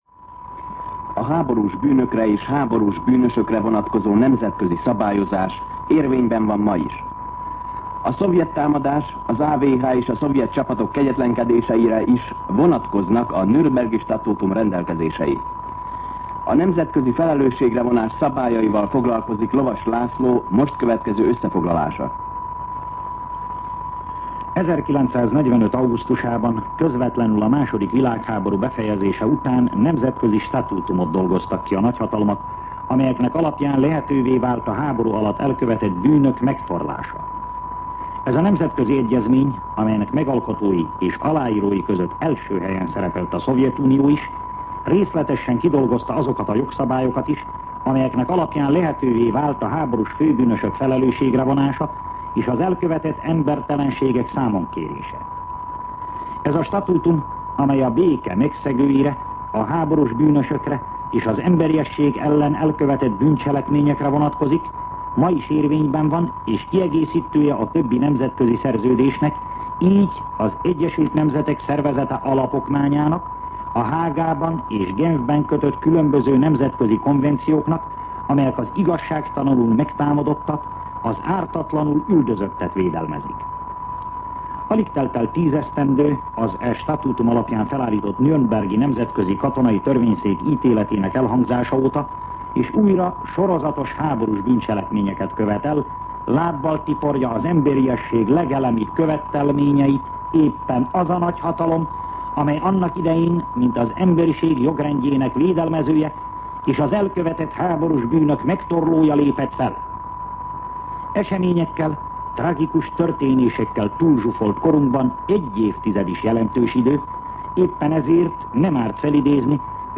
Szignál
Szünetjel